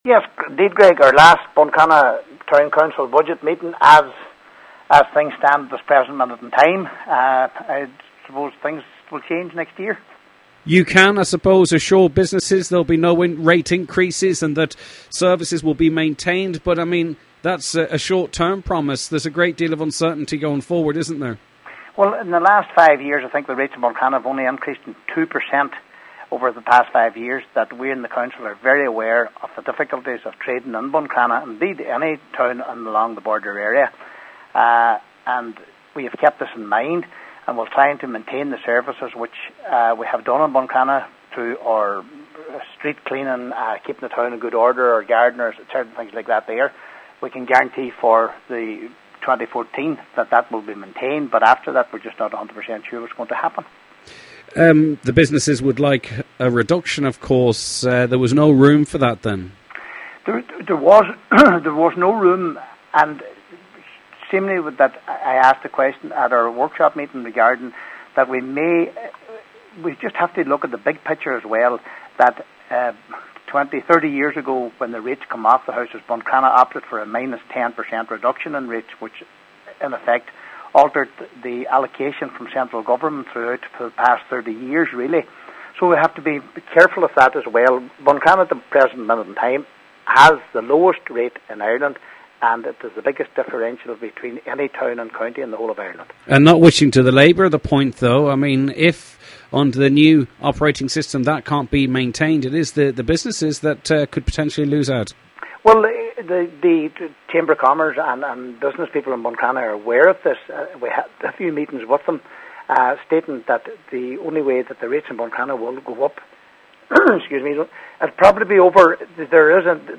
Buncrana Town Councillor Nicholas Crossan says however with the council being scrapped, the only way business rates will go into the future is up: